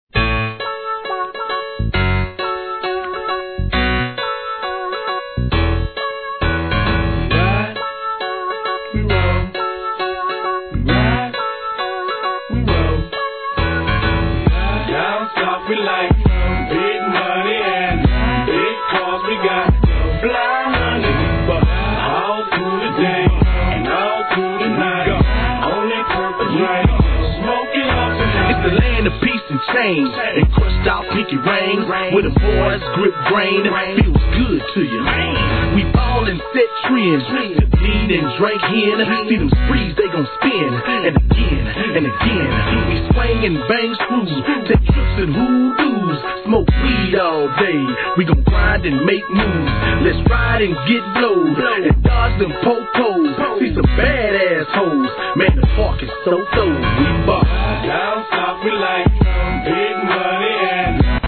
G-RAP/WEST COAST/SOUTH
見事なまでのスモーキンSHITでユルユルです♪ レア・シングル!!